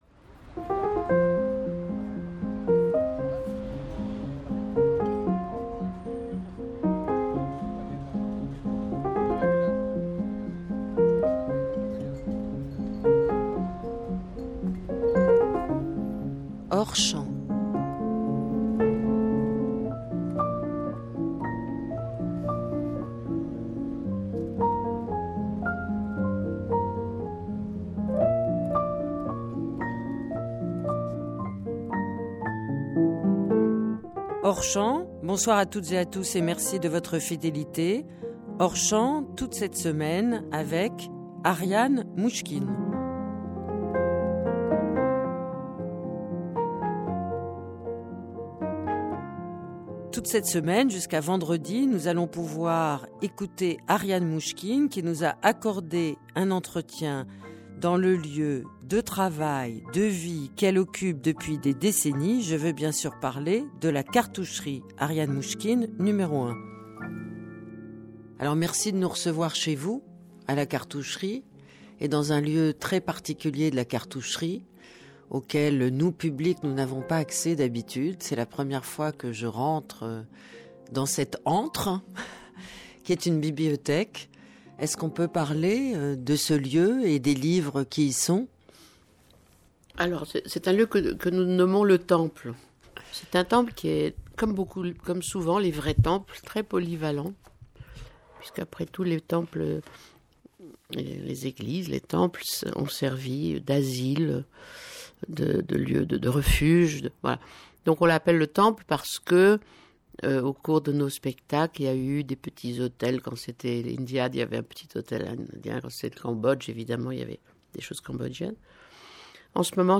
Plus précisement dans la bibliothèque, surnommée "le temple", qui sert aussi de refuge...